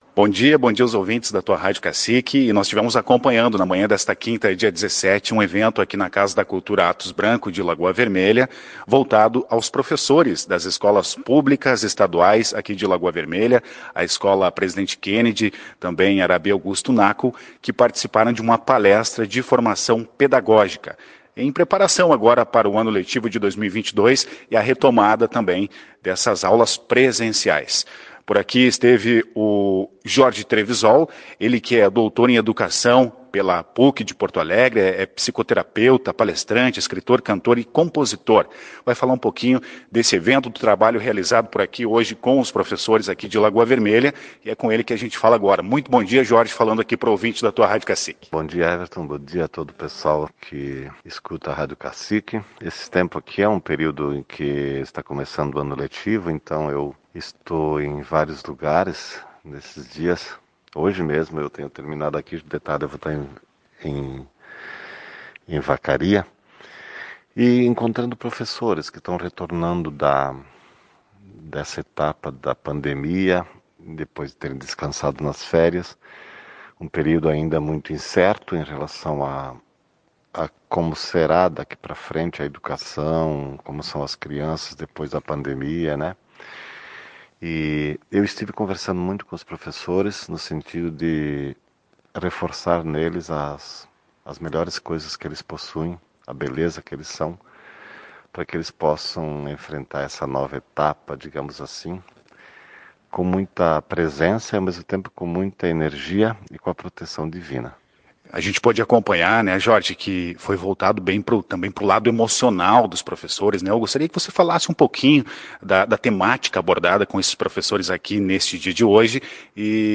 Em entrevista à reportagem da Tua Rádio Cacique, ele falou do trabalho realizado com os professores de Lagoa Vermelha.